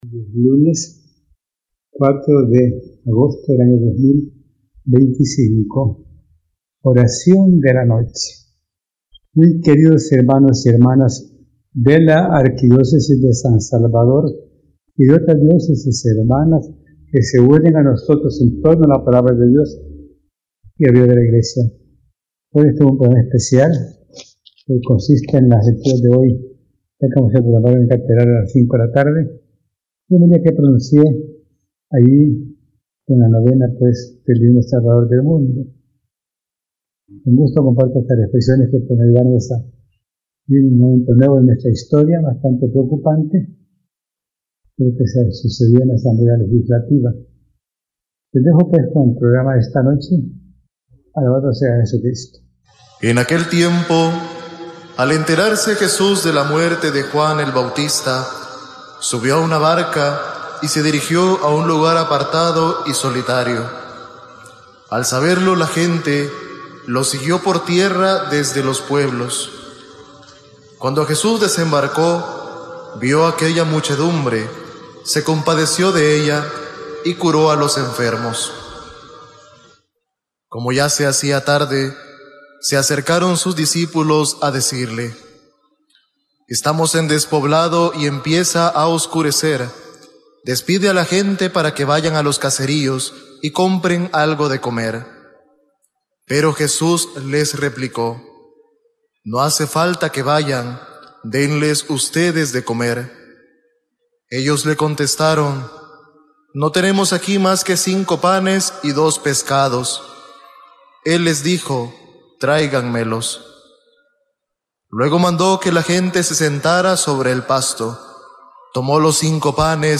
Homilía Mons Rosa Chávez 5 agosto.mp3